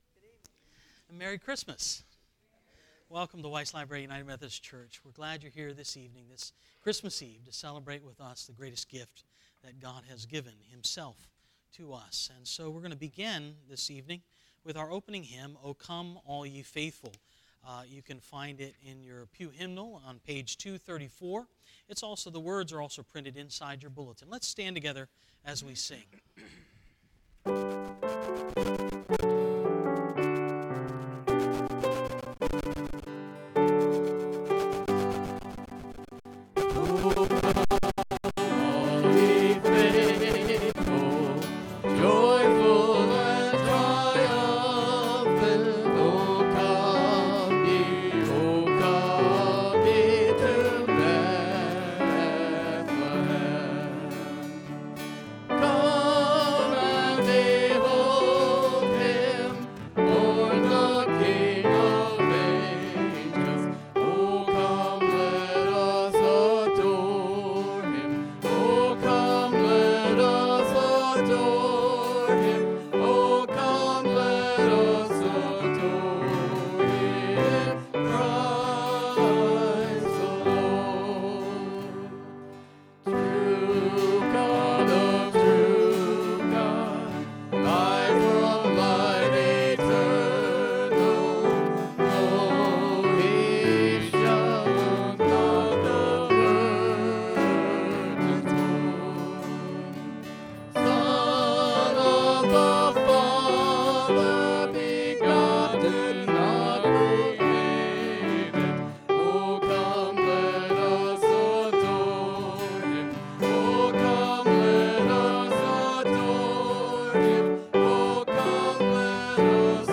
2018 Christmas Eve Service